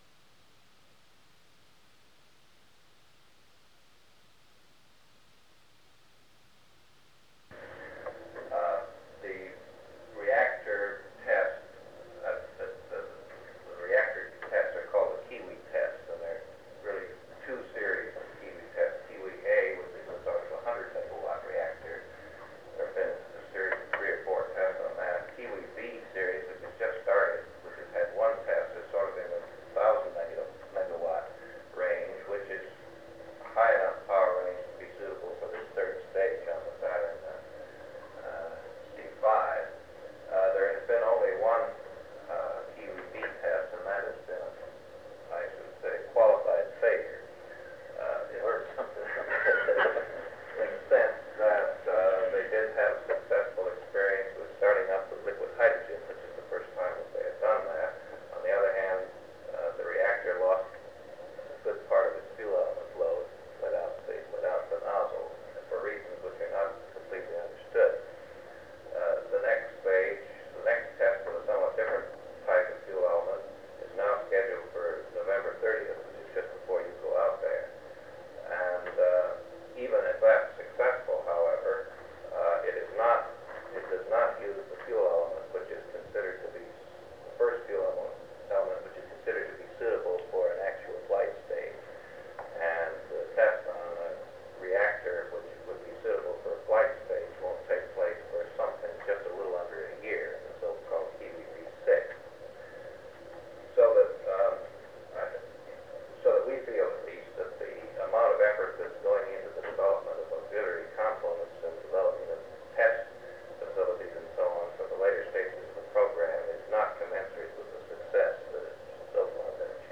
Secret White House Tapes | John F. Kennedy Presidency Meeting with the President’s Science Advisory Committee Rewind 10 seconds Play/Pause Fast-forward 10 seconds 0:00 Download audio Previous Meetings: Tape 121/A57.